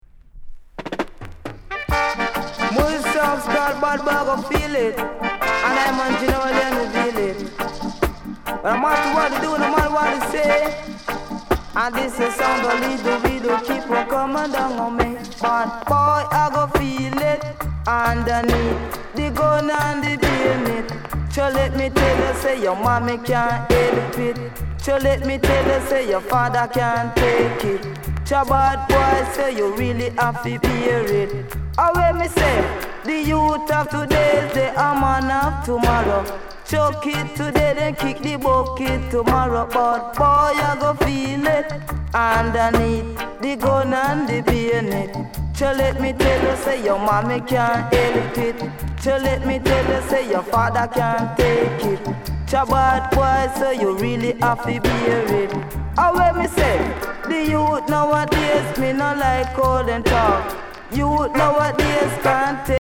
���פ����Ⱦ�ˤ����ƣ��ܽ�������ޤ� Coment RARE SWING EASY RIDDIM